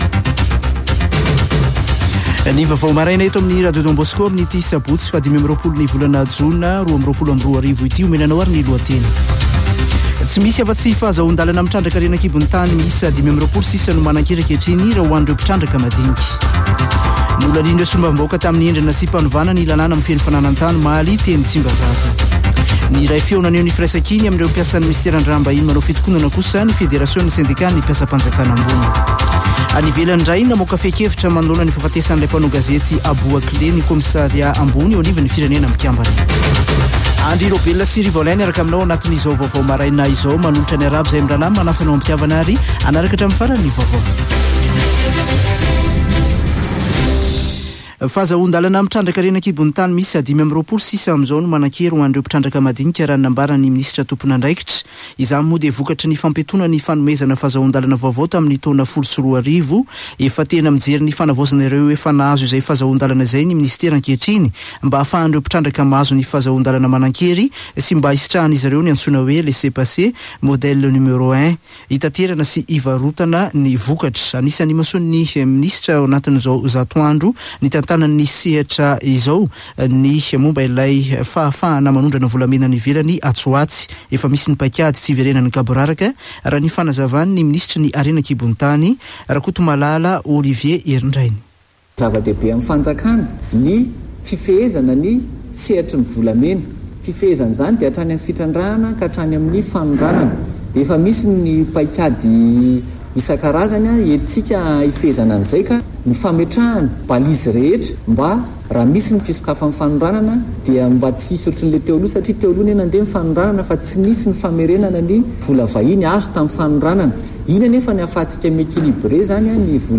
[Vaovao maraina] Sabotsy 25 jona 2022